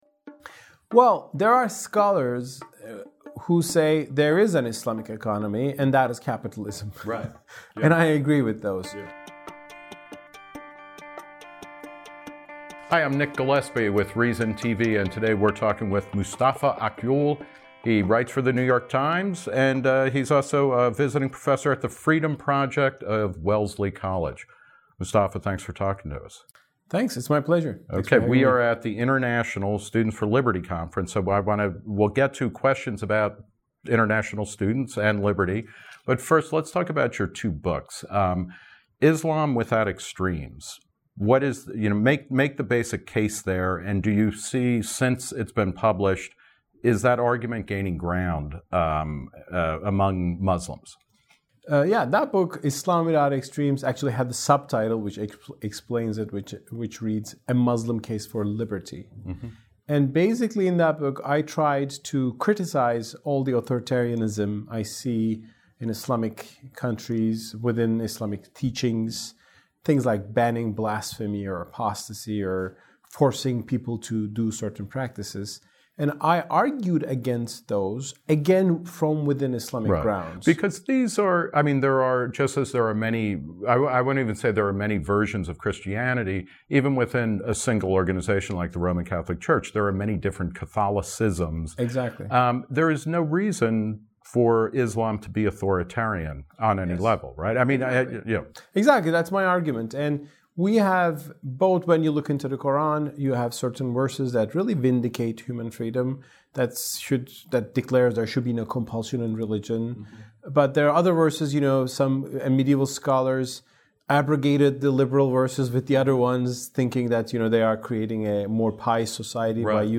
Q&A with author and New York Times columnist Mustafa Akyol.